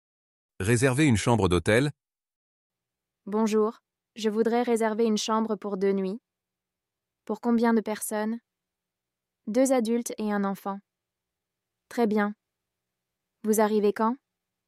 Dialogue en français – Réserver une chambre d’hôtel (suite)